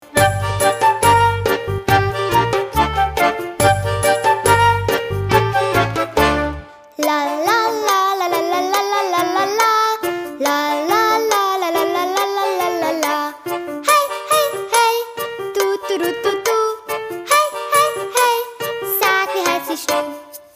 in Mundart gesungen von Kindern im Alter von 7 bis 10 Jahren